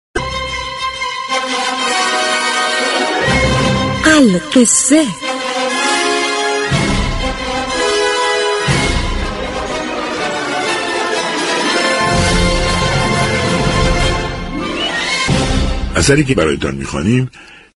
این برنامه با رویكرد معرفی و آشنا كردن مخاطبان با داستان های خواندنی و جالب هر روز به خوانش یك داستان كوتاه اختصاص می یابد.